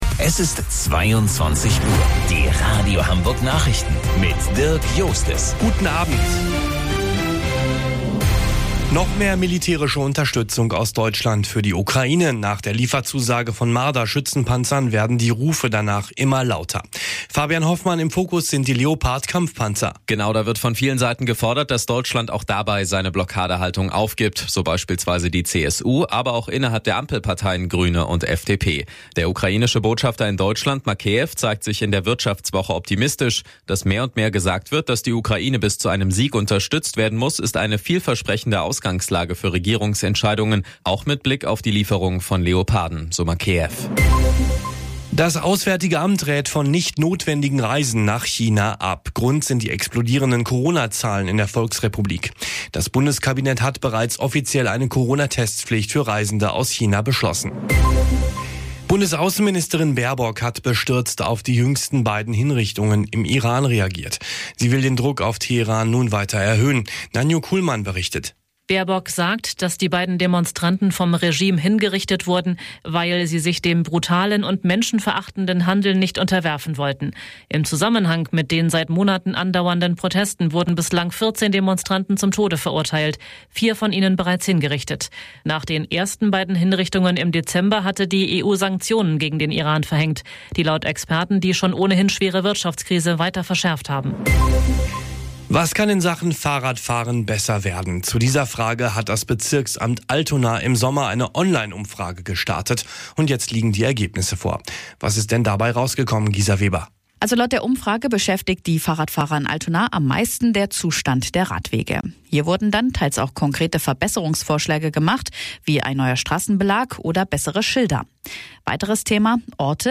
Radio Hamburg Nachrichten vom 19.06.2022 um 19 Uhr - 19.06.2022